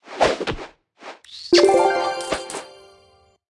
Media:Sfx_Anim_Ultra_Jessie.wav 动作音效 anim 在广场点击初级、经典、高手和顶尖形态或者查看其技能时触发动作的音效